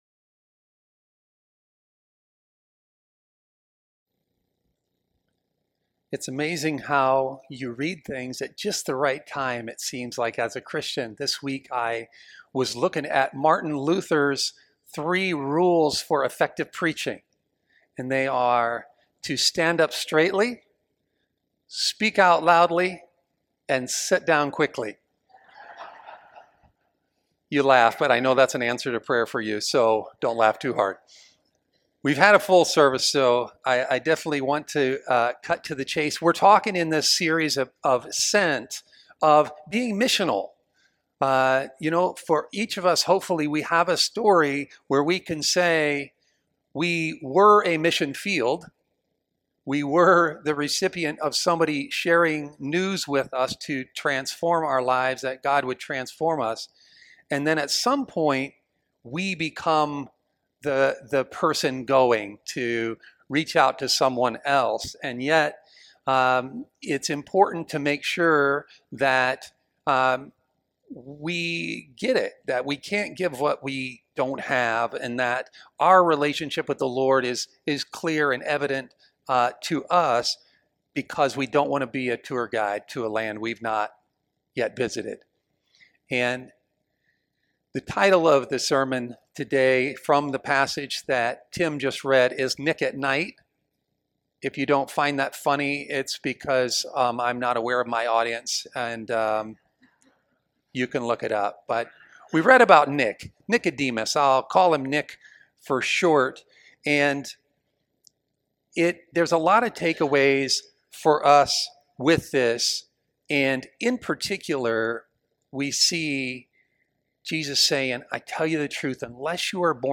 Sermon-4-19-26.mp3